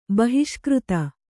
♪ bahiṣkřta